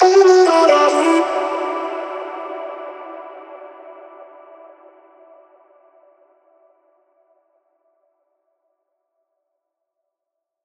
VR_vox_hit_melody_Emin.wav